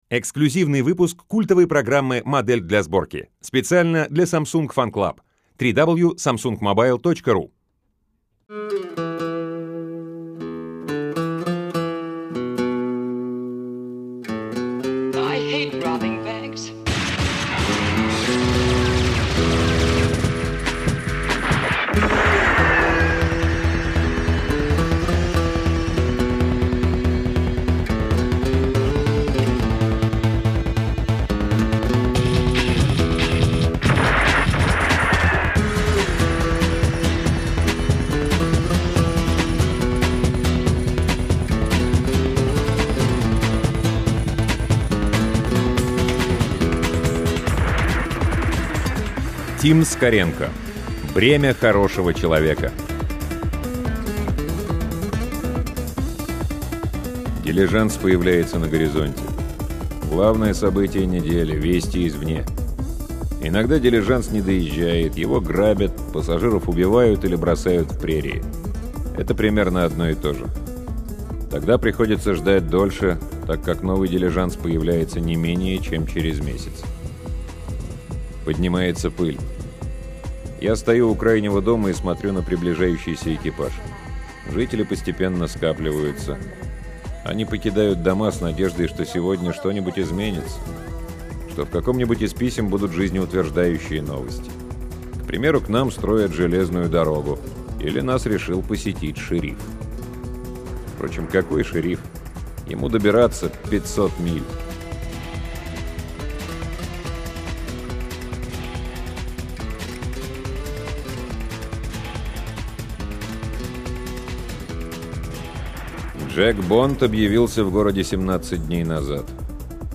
Аудиокнига Тим Скоренко — Бремя хорошего человека
Аудиокниги передачи «Модель для сборки» онлайн